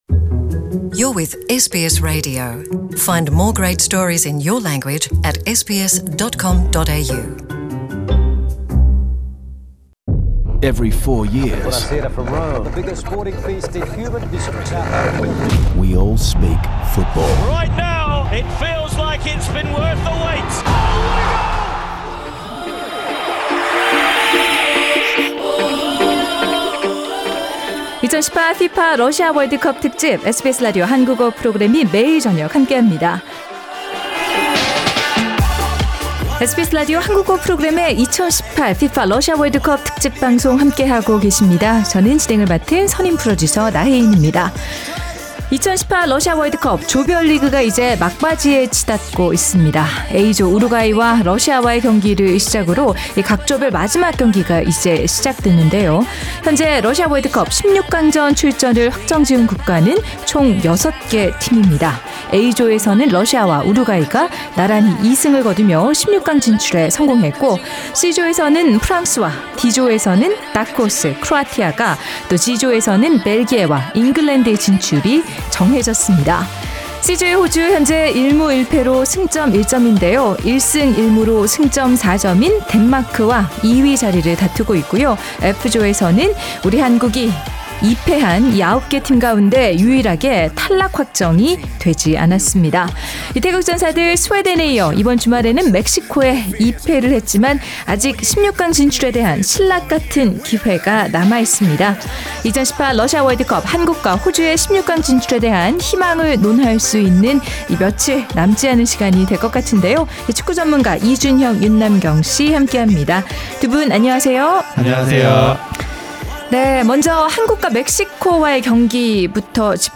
The panel also shares their analysis and prediction about tonight’s matches between IR Iran v Portugal and Spain v Morocco. The full World Cup Panel discussion is available on the podcast above.